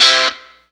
Track 02 - Guitar Stab OS 03.wav